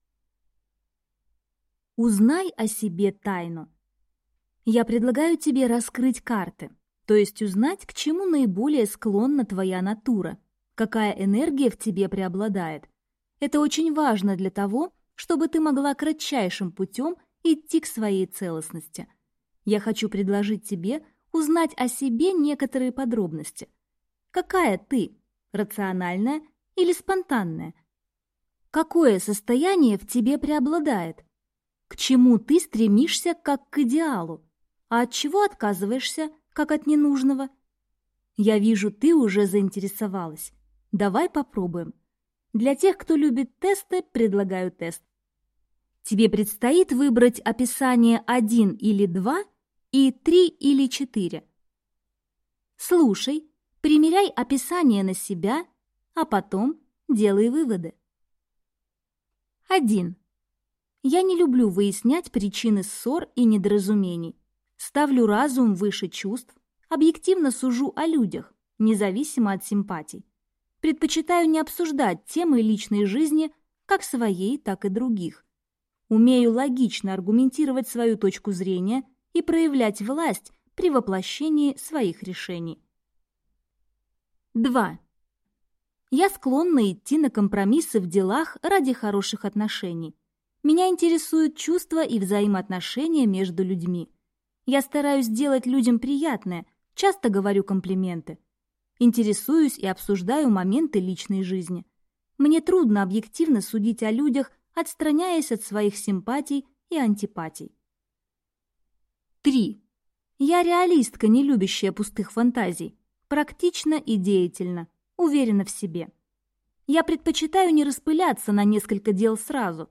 Аудиокнига Четыре грани совершенства. Годовая программа возвращения женственности | Библиотека аудиокниг